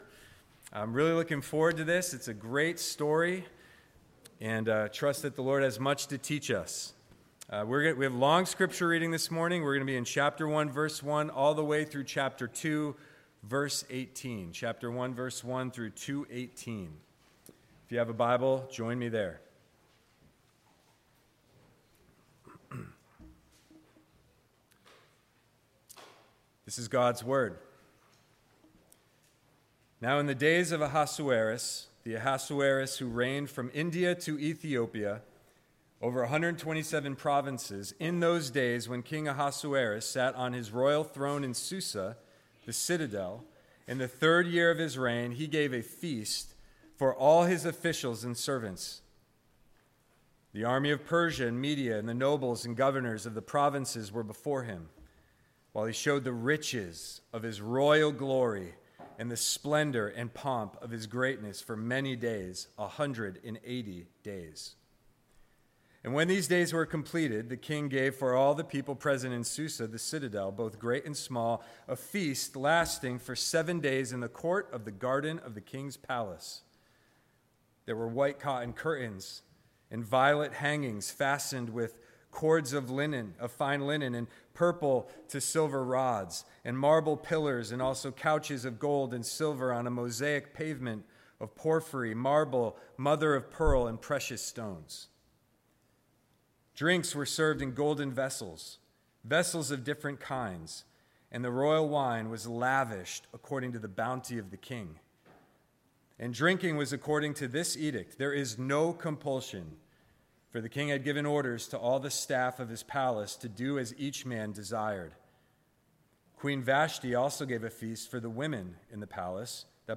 Sermon Archive | Cornerstone Church